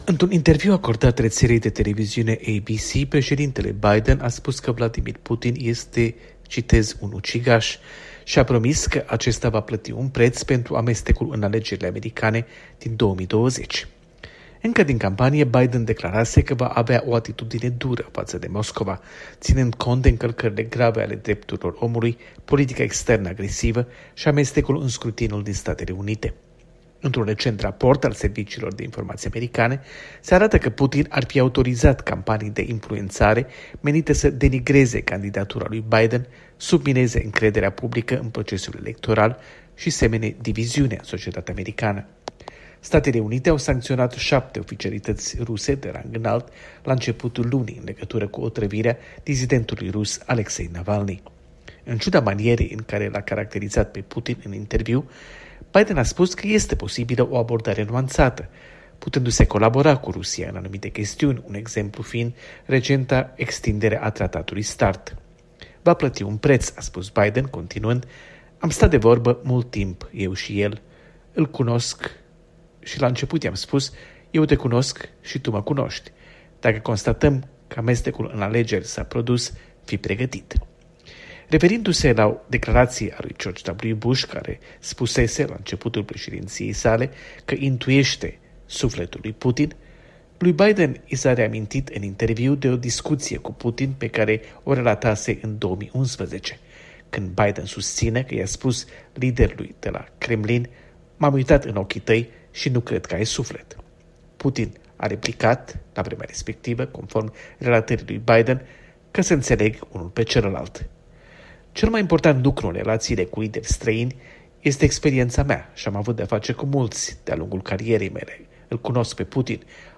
Corespondență de la Washington